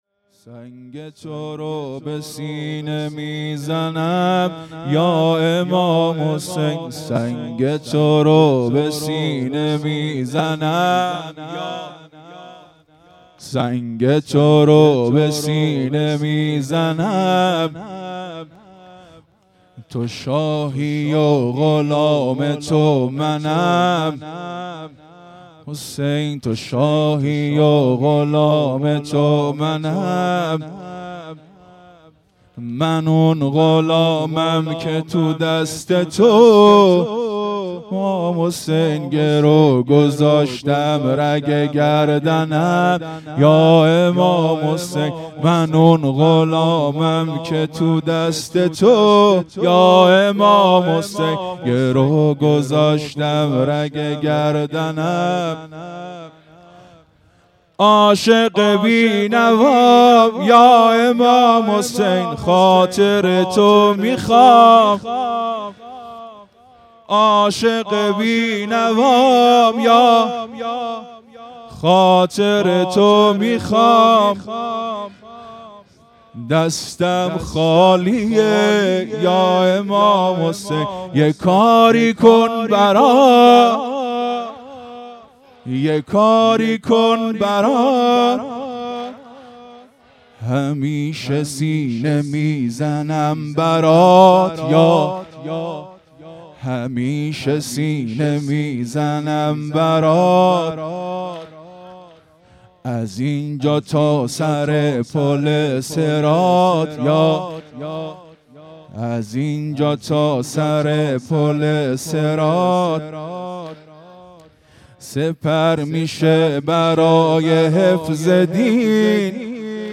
واحد | سنگ تورا به سینه میزنم یا امام حسین مداح
مراسم عزاداری محرم الحرام ۱۴۴۳_شب سوم